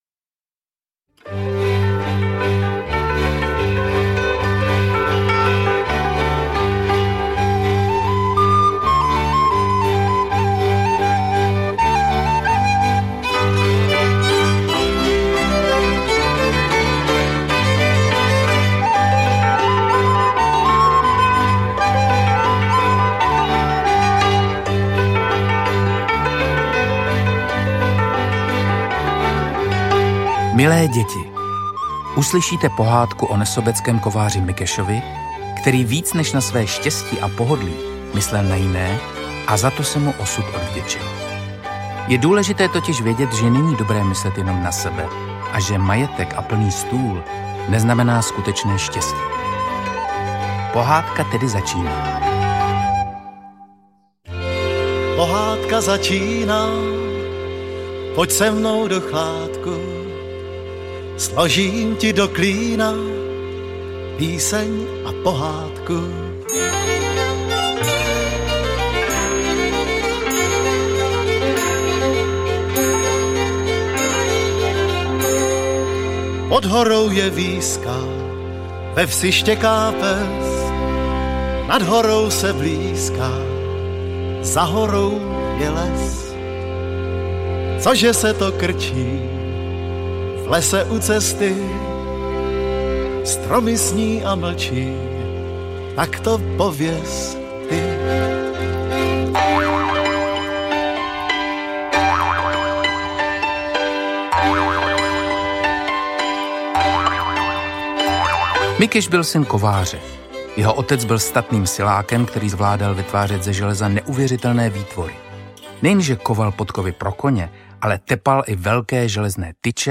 O statečném kováři audiokniha
Hudba Petr Ulrych Zpívají Hana a Petr Ulrychovi Hraje skupina Javory a Filmový symfonický orchestr, řídí Mario Klemens Slavná filmová pohádka ožívá ve vyprávění proslulého hlavního hrdiny, doprovázena původní hudbou Na motivy pohádky Boženy Němcové…
• InterpretPavel Kříž, Petr Ulrych